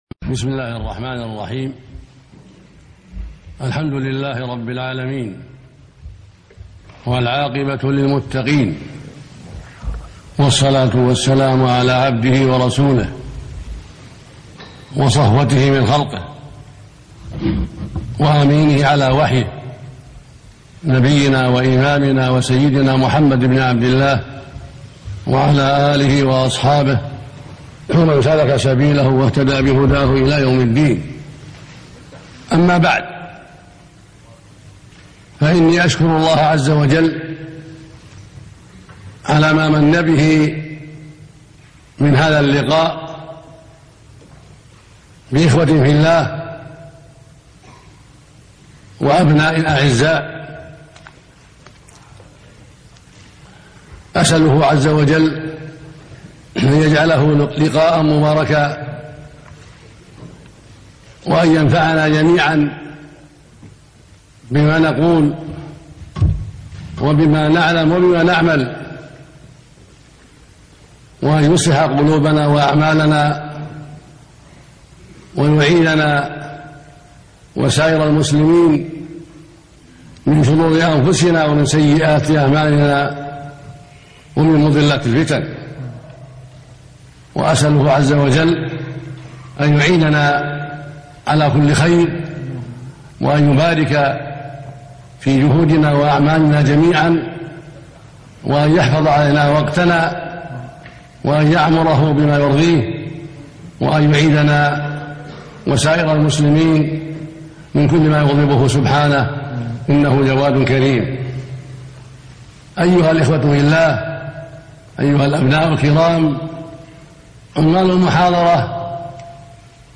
ar_BenBaz_MohadratAljame3_17.mp3